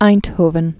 (īnthōvən)